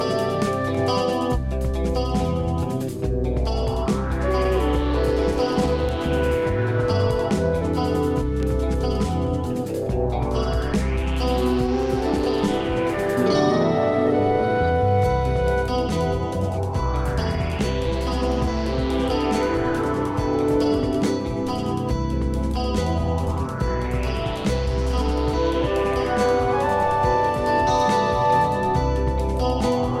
パート1となるこちらはギター、キーボード、ドラム、エレクトロニクスが巧みに絡み合う全7曲を収録。